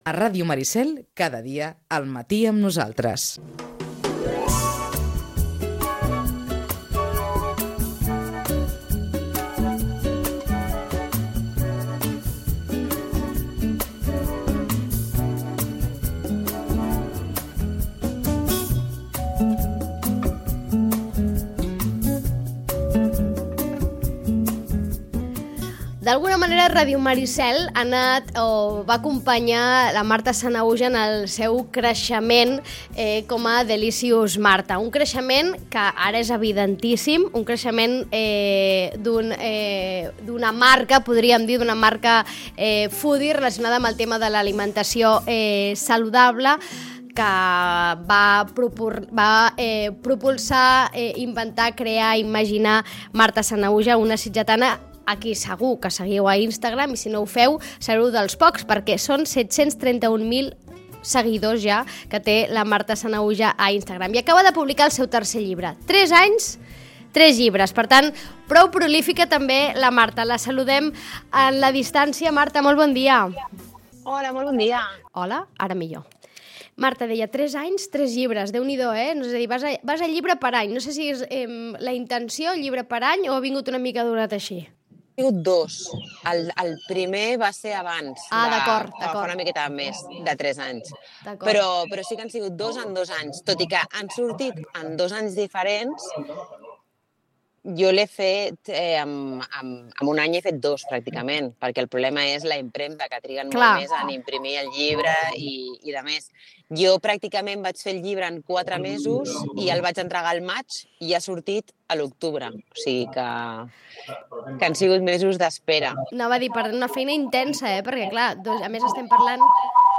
Hem conversat amb ella sobre el llibre i sobre la intensa vida d’una creadora de continguts.